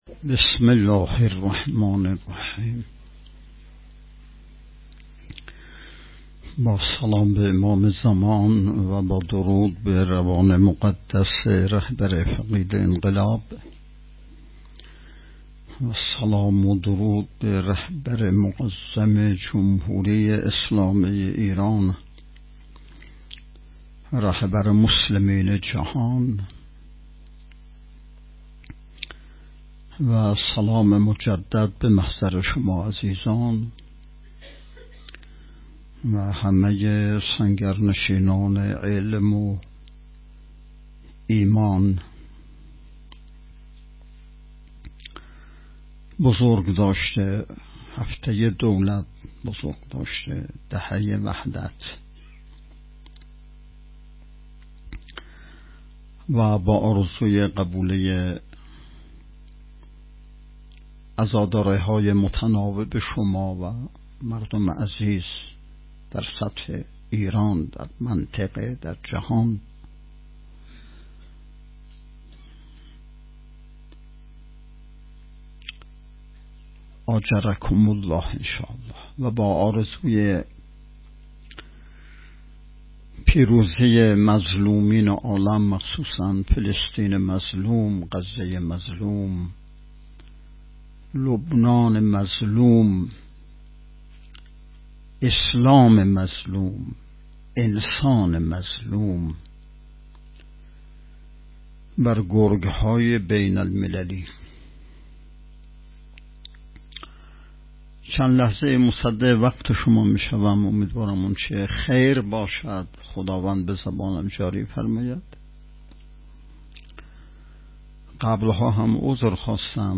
بیست و یکمین نشست ارکان شبکه تربیتی صالحین بسیج با موضوع تربیت جوان مؤمن انقلابی پای کار، صبح امروز ( ۶ شهریور) با حضور و سخنرانی نماینده ولی فقیه در استان، برگزار شد.